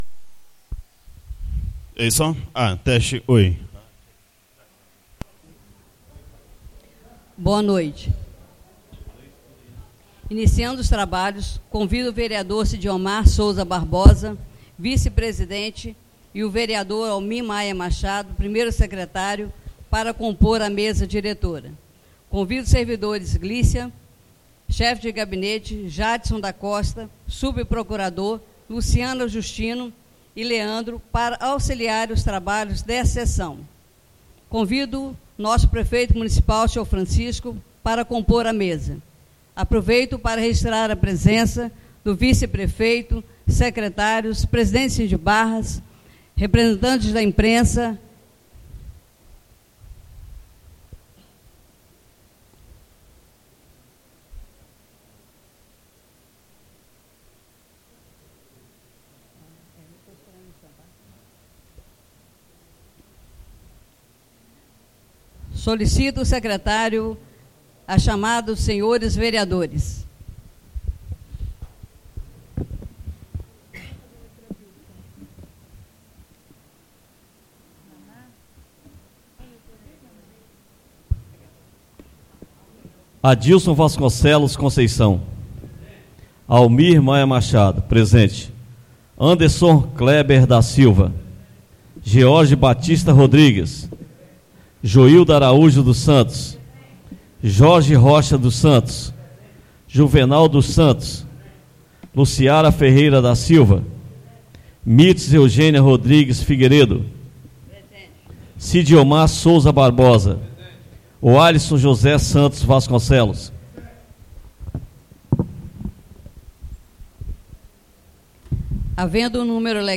2ª (SEGUNDA) SESSÃO EXTRAORDINÁRIA PARA A DATA DE 13 DE JANEIRO DE 2017.